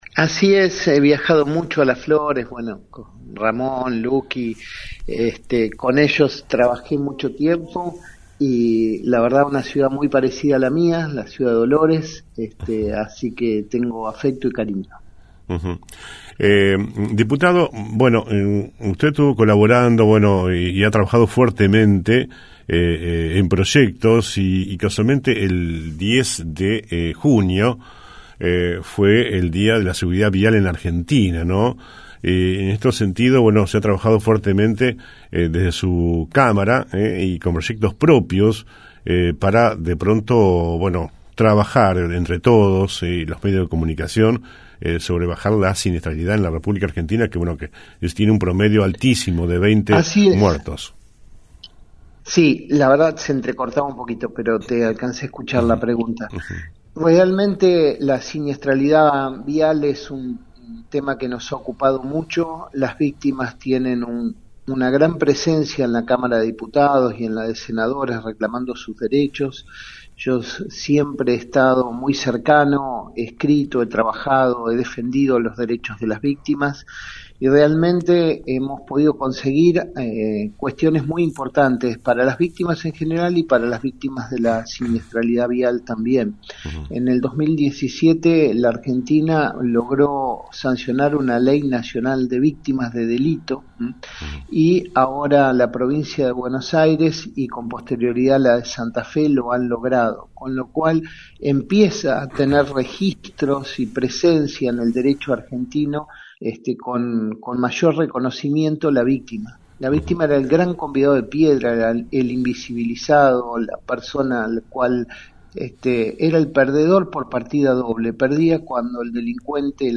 En la mañana de “El Periodístico” hablamos con el diputado nacional del Frente Renovador oriundo de Dolores Ramiro Gutiérrez.